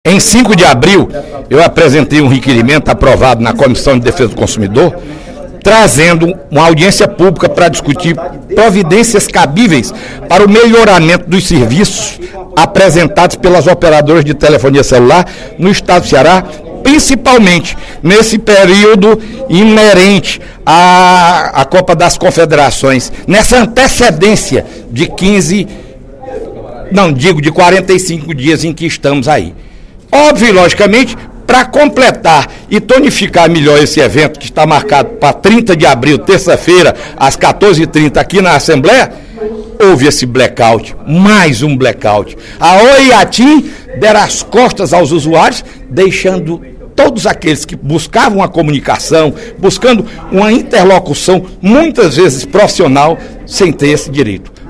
O deputado Fernando Hugo (PSDB) informou, nesta sexta-feira (26/04), durante o primeiro expediente da Assembleia Legislativa, que a Comissão de Defesa do Consumidor realizará, na próxima terça-feira às 14h30, audiência pública para debater os serviços de telefonia móvel ofertados no Ceará.